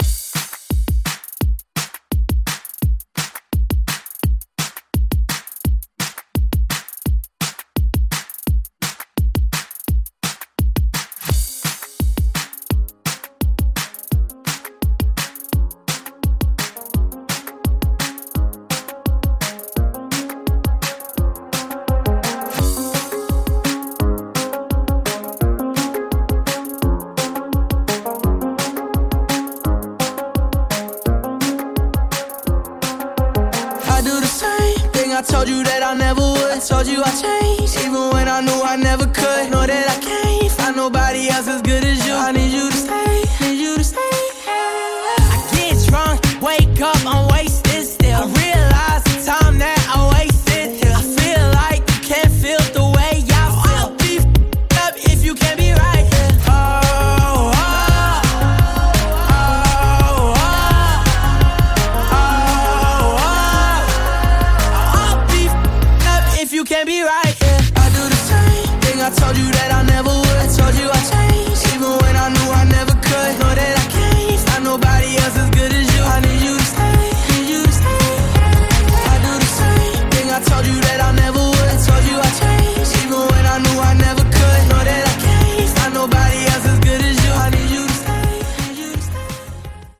Genre: HIPHOP Version: Clean BPM: 80 Time